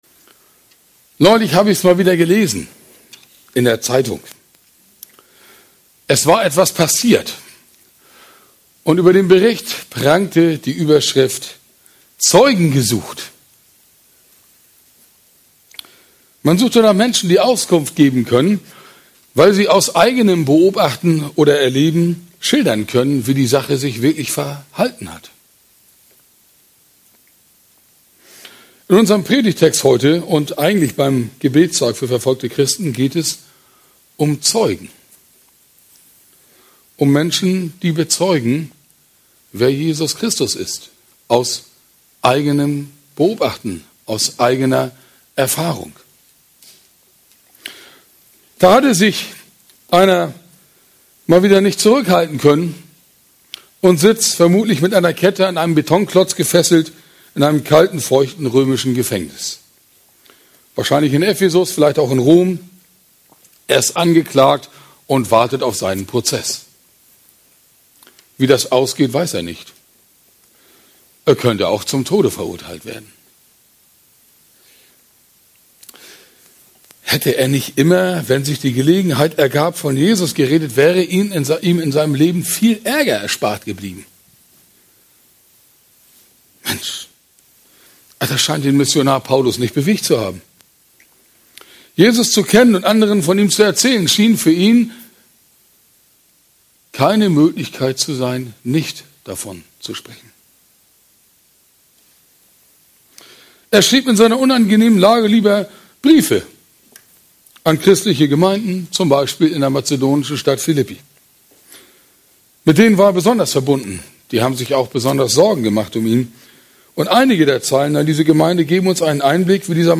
Predigt vom 4. November 2018